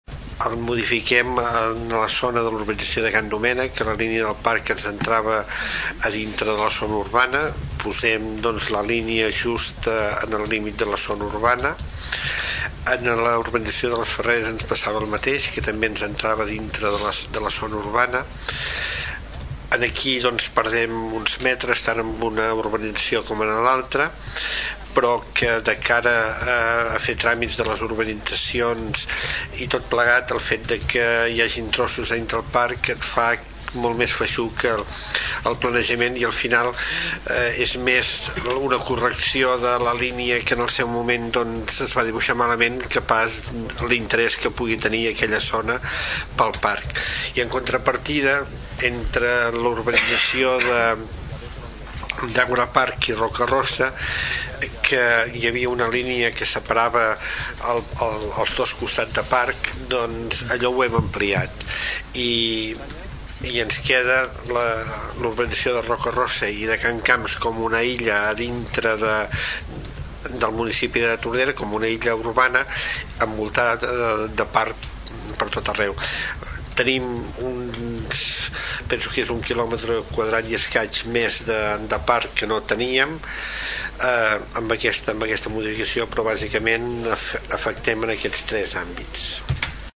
A banda, la modificació de l’àmbit natural, preveu a Tordera diversos canvis a les urbanitzacions Can Domènec, les Ferreres, Àgora Parc i Roca-Rossa així ho comenta el regidor Carles Aulet.